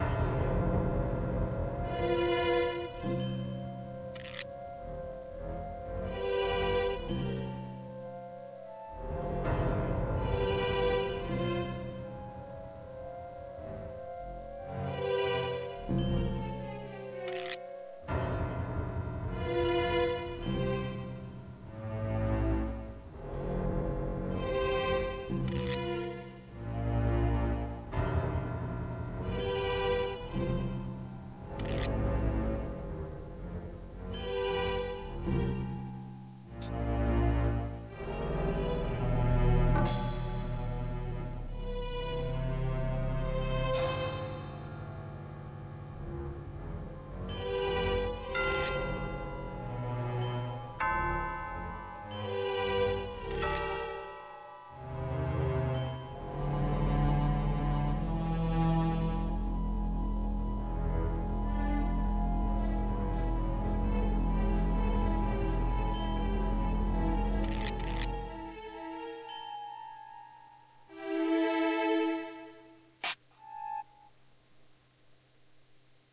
Another Short Creepy tune